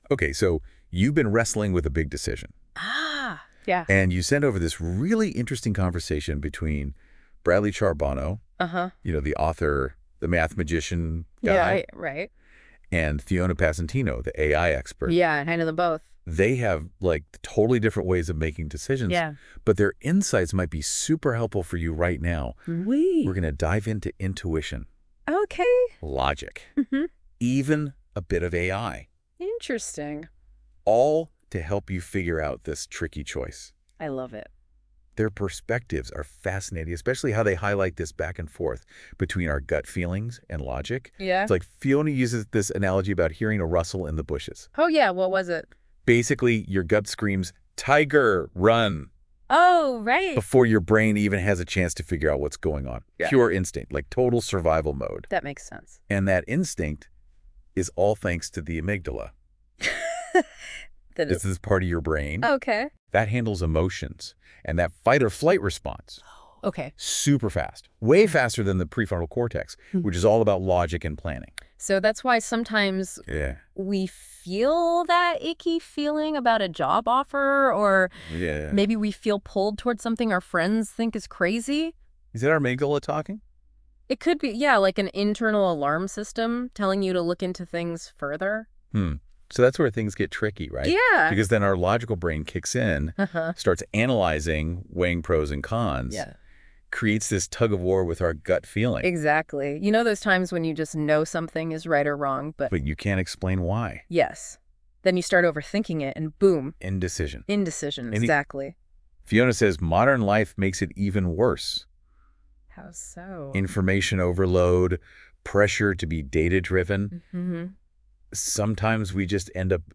re407: Intuition, Logic, and the Evolving Role of AI in Decision-Making (thanks to Notebook LM)